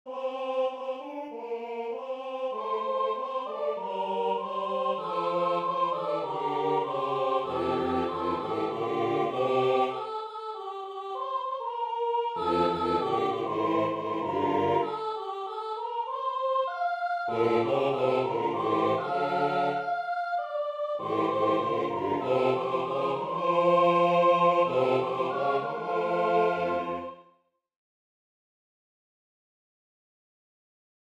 Для мужского квартета, a cappella